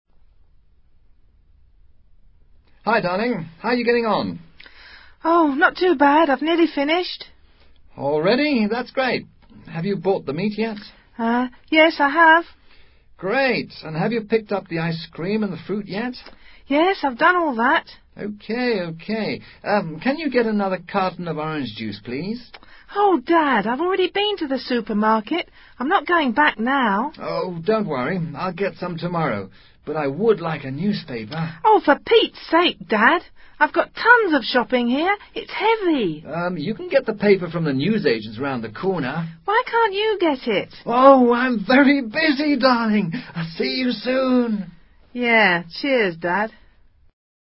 Una mujer y su padre mantienen una conversación telefónica.